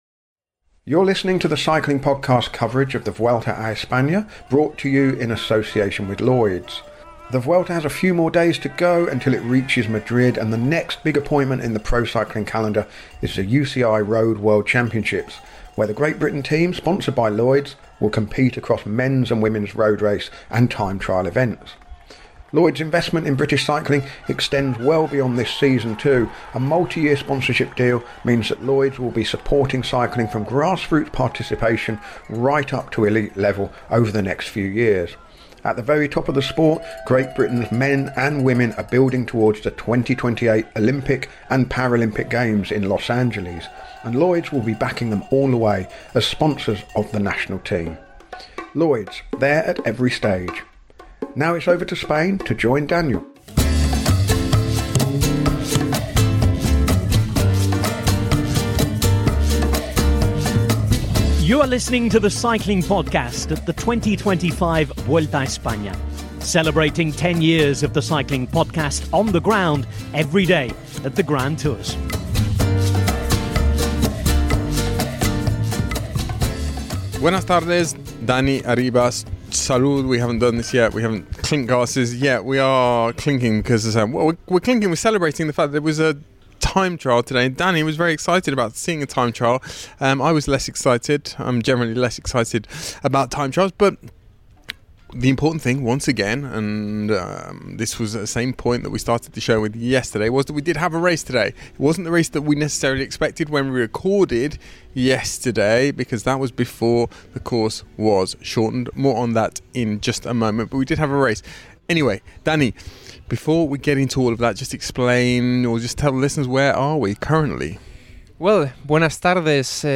Join us for daily coverage of the Vuelta a España recorded on the road as the race makes its way from Turin to Madrid. Our daily coverage features race analysis, interviews and daily postcards from Spain.